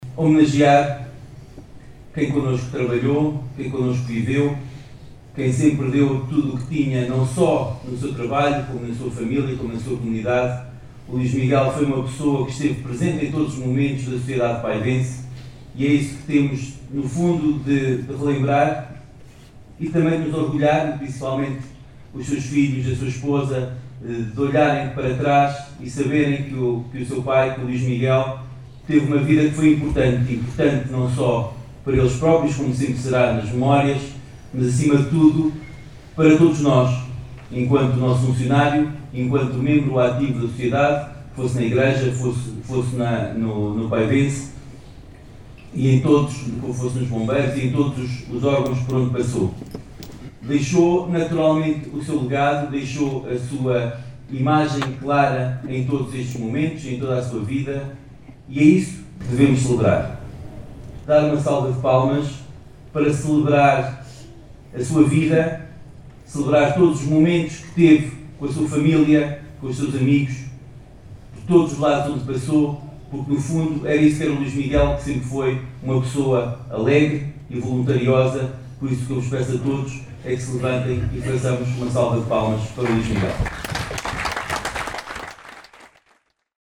Com o olhar virado para o futuro e para o desenvolvimento sustentável da comunidade, esta segunda-feira, 2 de março, comemorou-se em Vila Nova de Paiva o Feriado Municipal, com as cerimónias oficiais que incluíram o hastear da bandeira, sob guarda de honra dos Bombeiros Voluntários, seguido de missa na Igreja Matriz, em memória dos autarcas e funcionários já falecidos, com a participação do Grupo Coral Redemptoris Mater.
P.-Marques-Homenagem.mp3